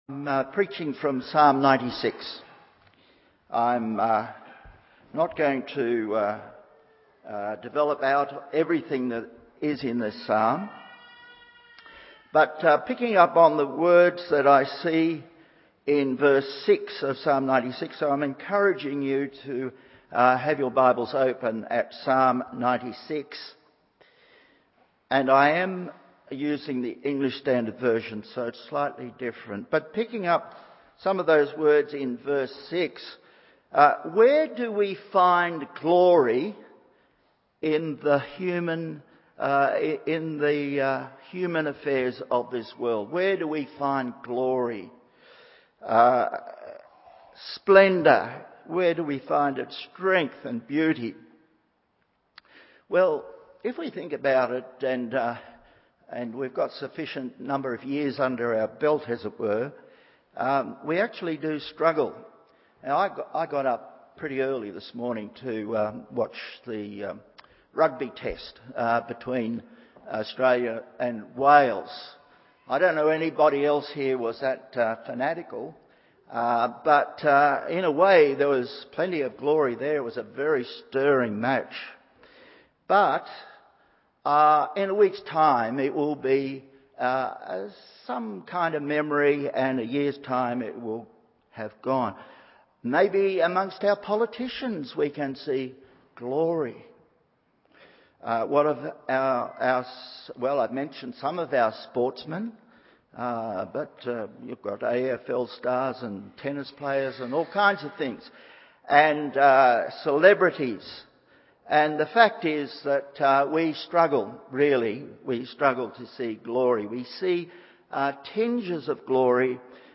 Morning Service Psalm 96:1-13…